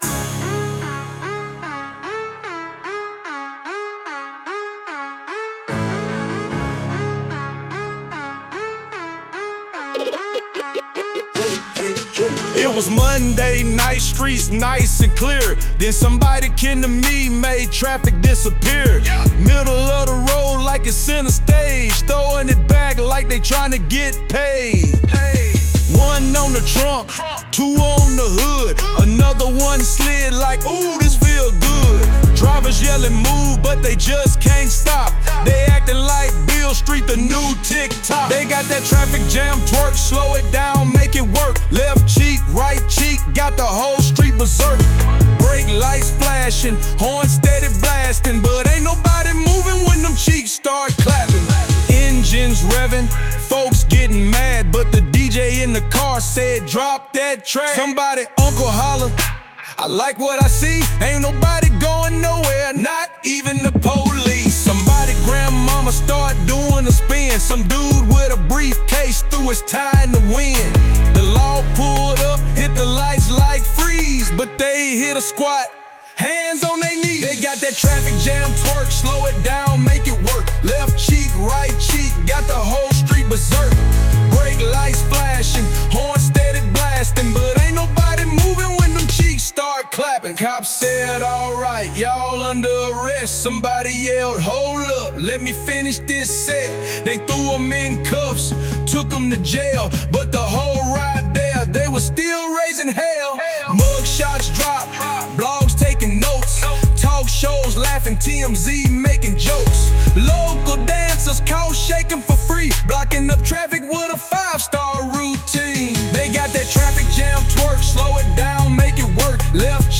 Hiphop
Description : twerk song